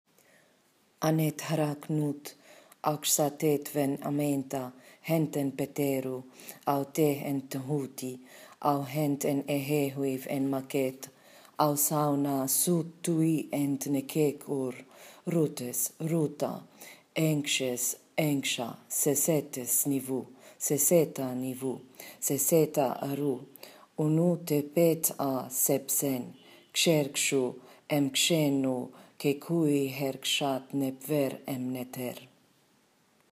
Healing prayer
Healing prayer read by me